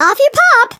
flea_ulti_vo_09.ogg